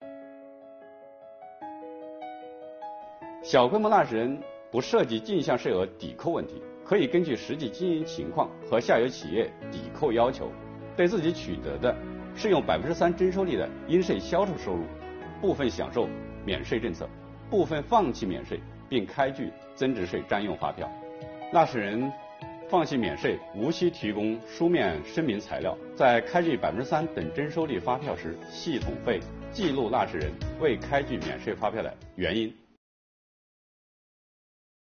本期课程由国家税务总局货物和劳务税司副司长刘运毛担任主讲人，对小规模纳税人免征增值税政策进行详细讲解，方便广大纳税人进一步了解掌握相关政策和管理服务措施。